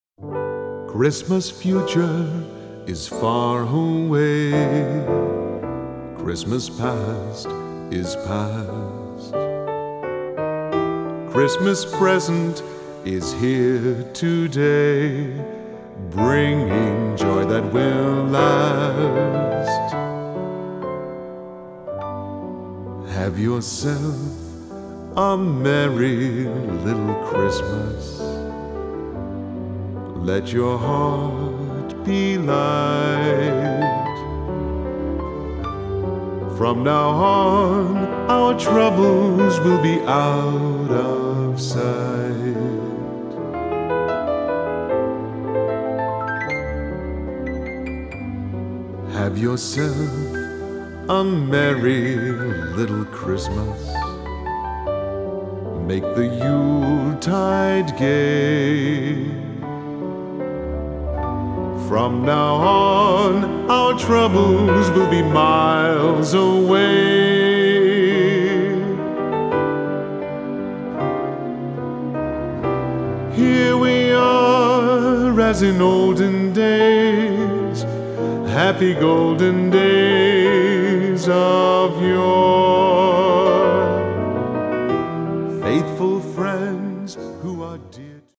piano/keyboard/vocals